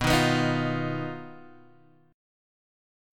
D#sus2#5/B chord